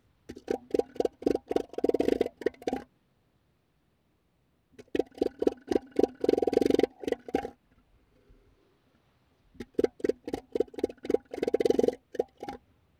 Acoustic communication
The most striking aspect of acoustic communication in southern elephant seals is male use of vocalizations to establish and maintain dominance. Agonistic contests have a stereotyped structure, and almost invariably involve the emission of sounds by the contestants.
Examples of male vocalizations